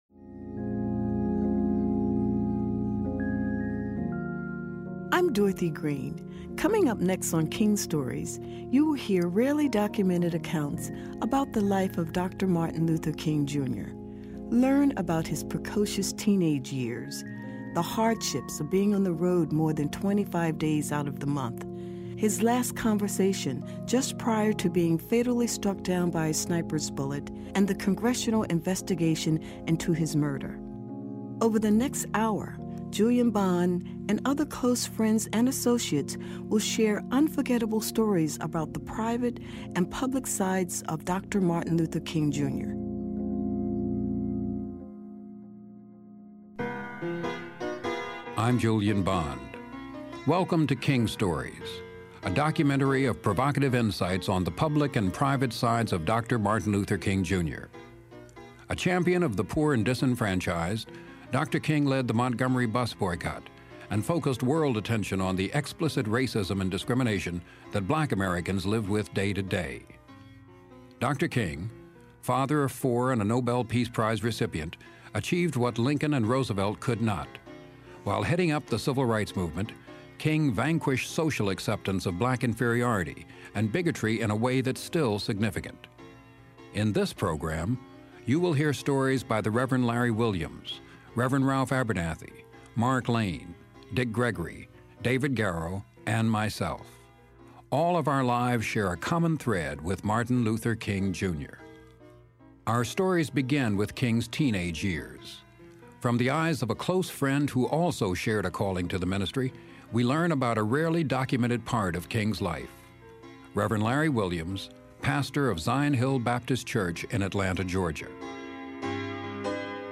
King Stories is a one hour documentary of captivating stories told by close friends and associates of Dr. Martin Luther King, Jr.